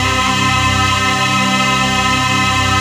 DM PAD2-85.wav